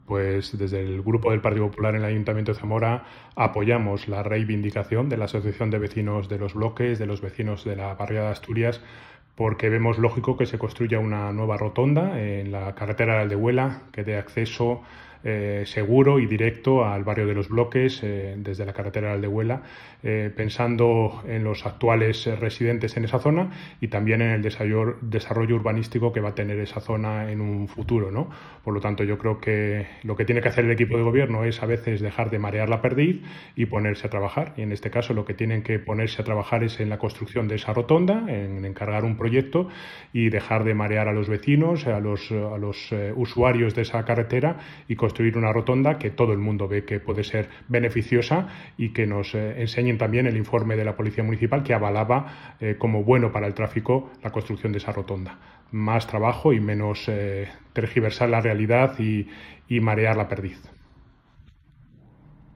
Declaraciones del portavoz Jesús Mª Prada